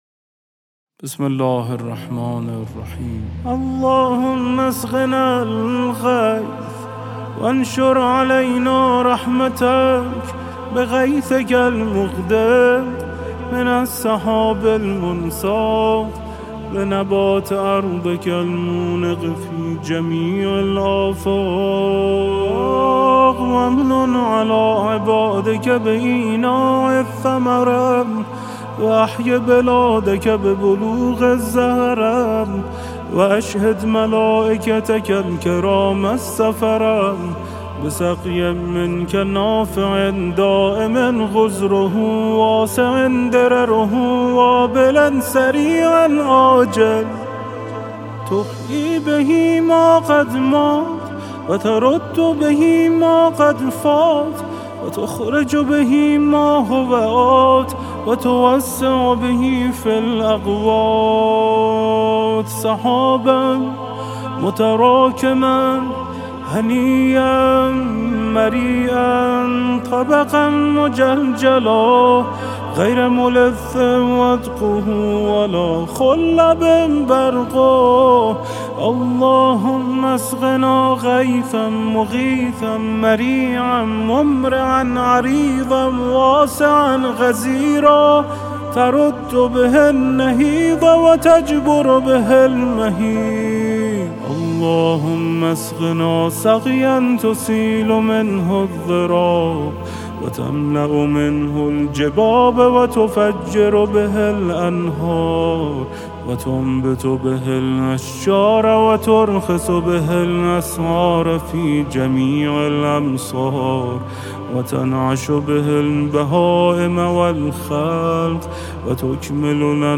mdahy-anlayn---d`ay-tlb-baran-ps-az-khshksaly---`ly-fany.mp3